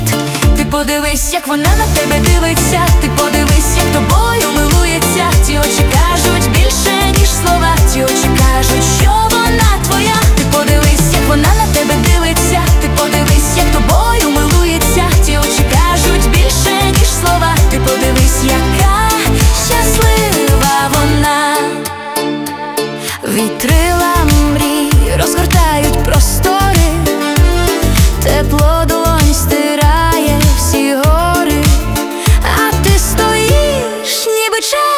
Pop Dance
Жанр: Поп музыка / Танцевальные / Украинские